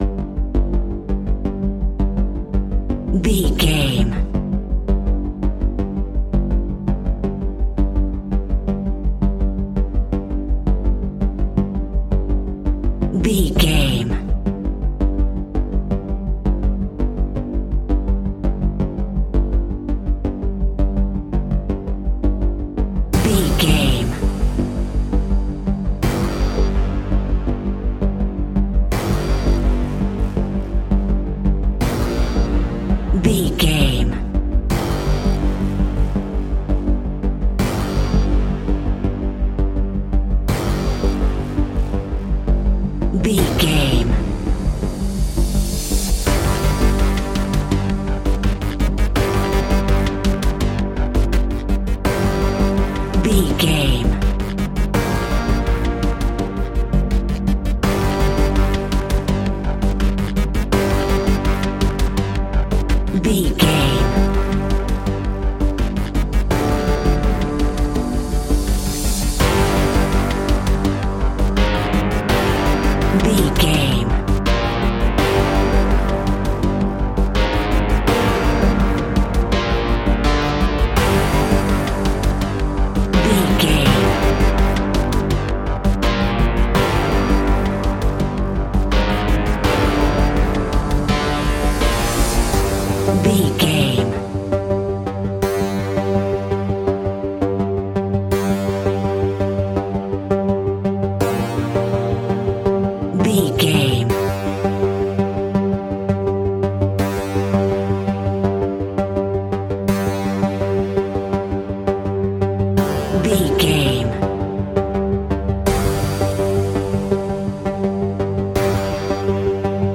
In-crescendo
Aeolian/Minor
scary
ominous
dark
suspense
haunting
eerie
ticking
electronic music
electronic instrumentals
Horror Pads
Horror Synths